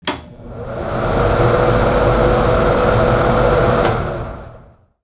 fan-sound